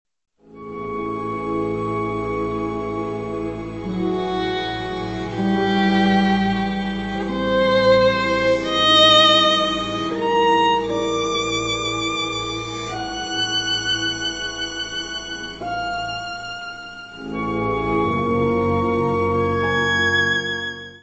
Adagio.